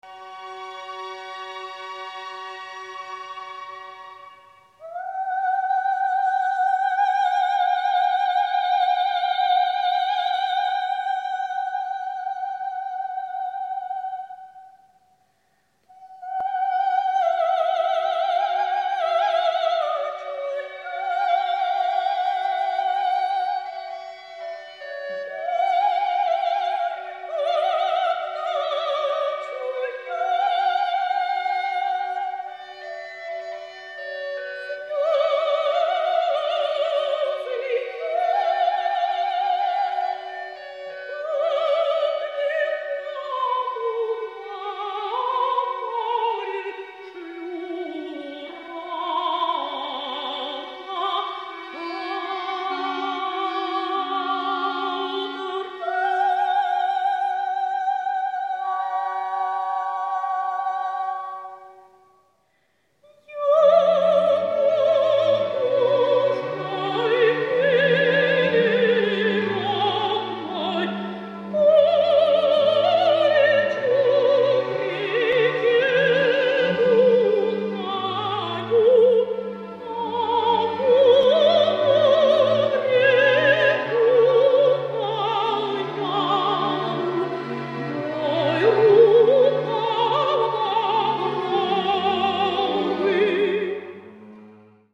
! можете не гуглить - это ария Ярославны "Плач Ярославны" из оперы "Князь Игорь" композитора Бородина - все это не играет, логика в другом !